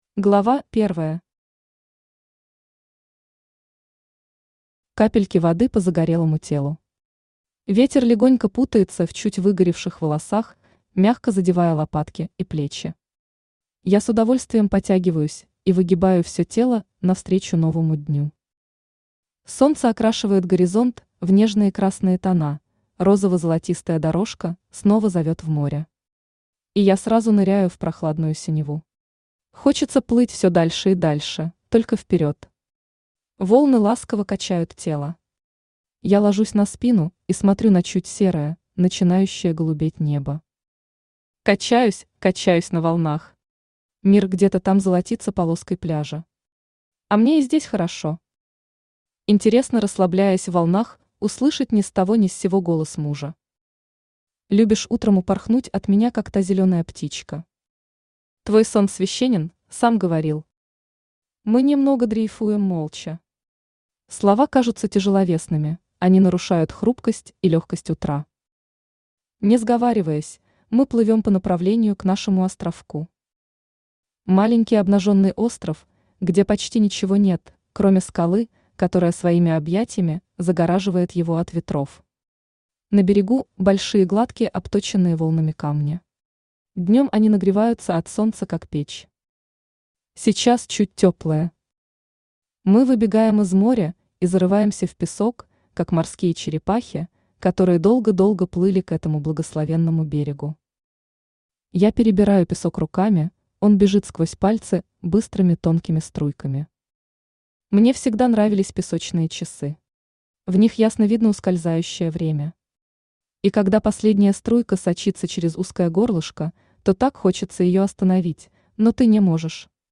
Аудиокнига Как я встретила тебя | Библиотека аудиокниг
Aудиокнига Как я встретила тебя Автор Нина Романова Читает аудиокнигу Авточтец ЛитРес.